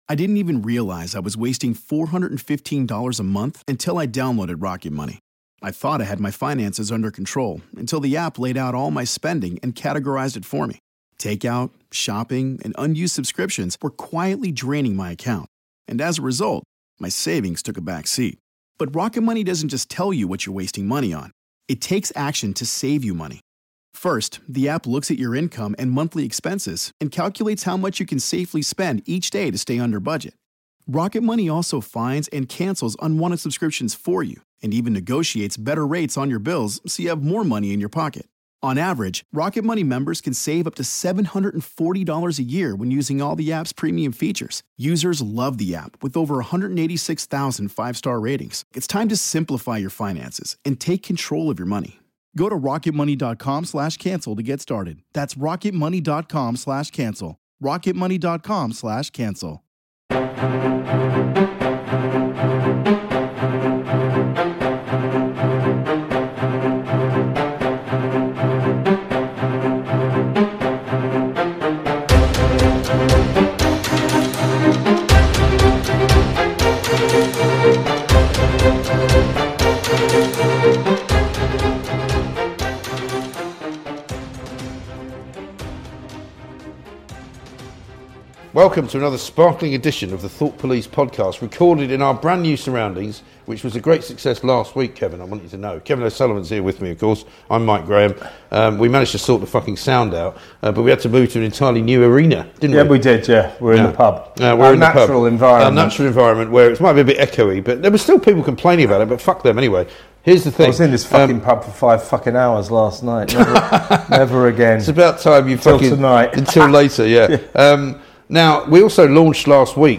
The lads are back with another rage filled deep dive into the past weeks top headlines.